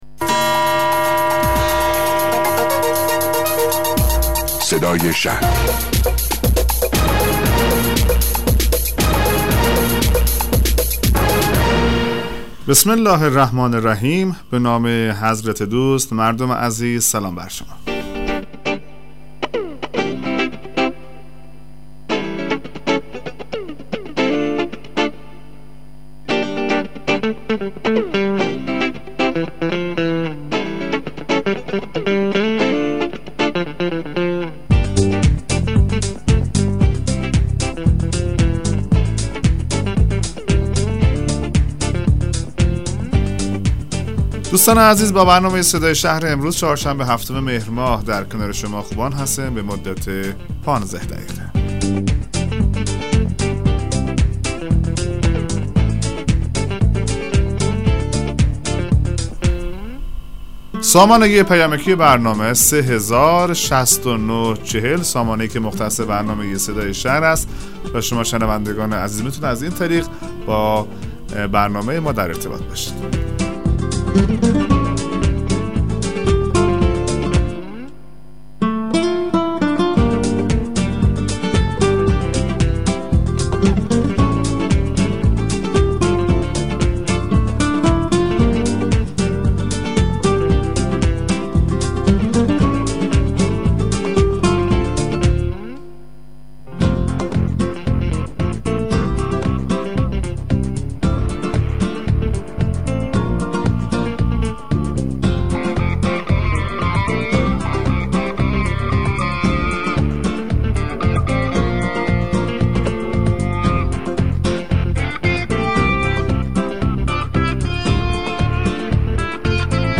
مصاحبه رادیویی برنامه صدای شهر با حضور مجید تجملیان رییس کمیسیون شهر هوشمند شورای اسلامی شهر یزد